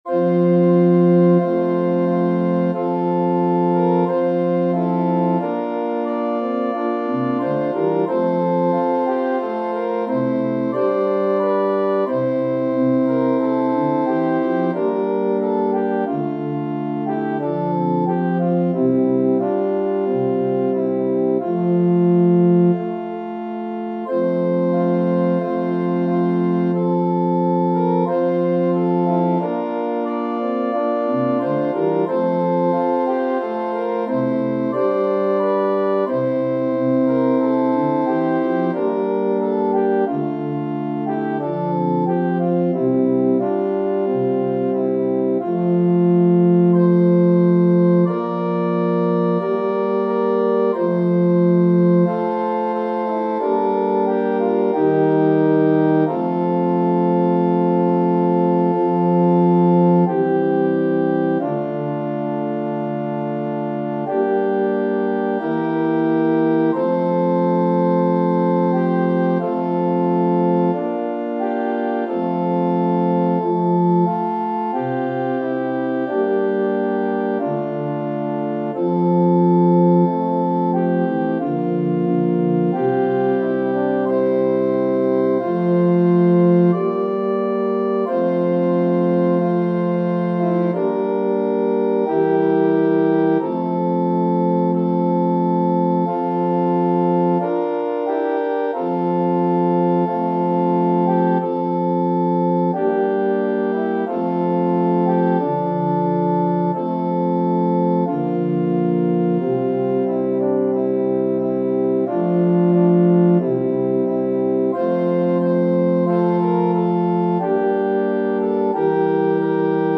Transposition: Altus/Tenor/Bassus up octave from print.
Organ reduction.